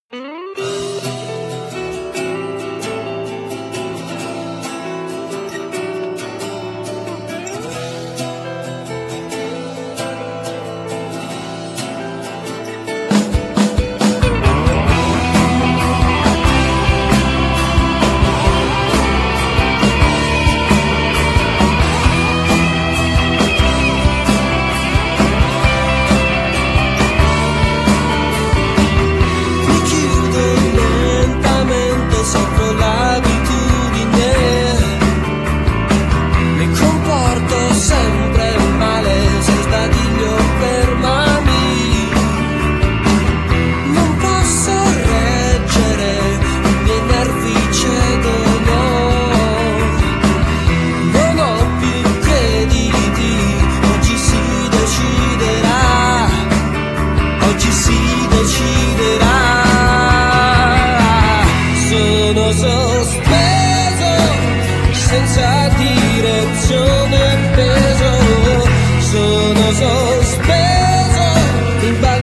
Genere : Pop
pop rock gradevole e di presa immediata
Buono l'equilibrio tra parti vocali e suonato.